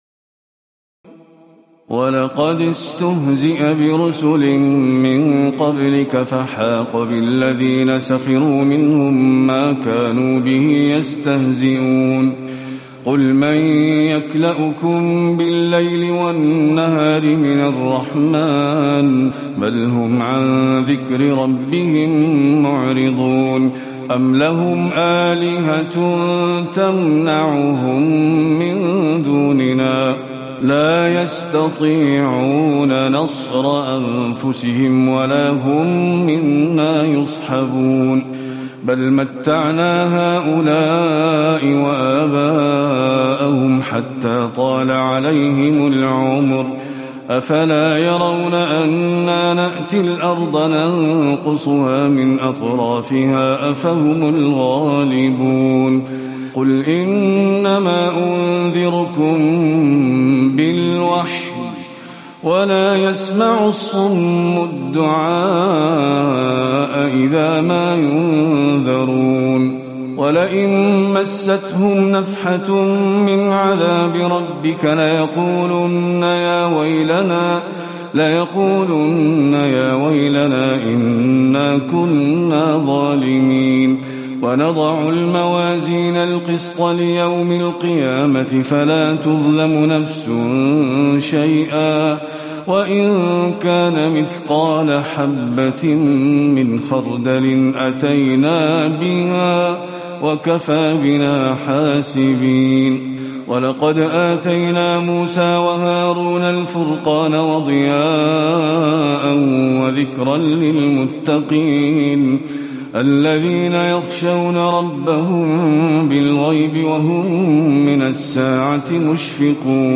تراويح الليلة السادسة عشر رمضان 1438هـ من سورتي الأنبياء (41-112) و الحج (1-37) Taraweeh 16 st night Ramadan 1438H from Surah Al-Anbiyaa and Al-Hajj > تراويح الحرم النبوي عام 1438 🕌 > التراويح - تلاوات الحرمين